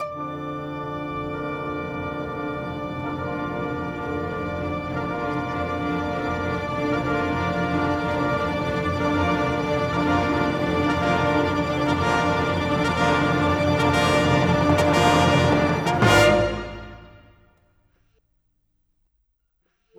Cinematic 27 Orchestra 01.wav